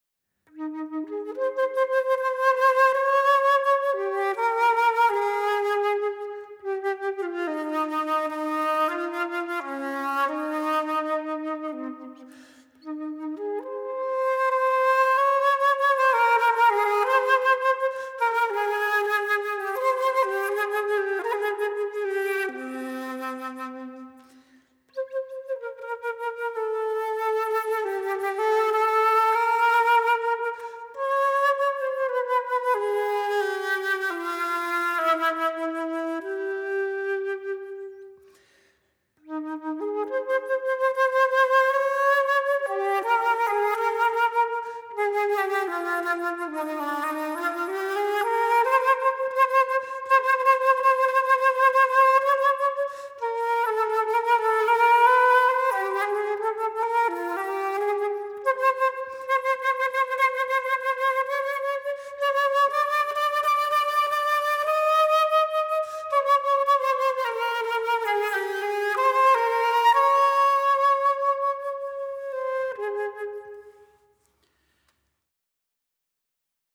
Perfect example of elasticity in phrasing and space between notes.